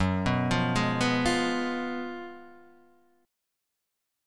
F# Major 7th Suspended 2nd Suspended 4th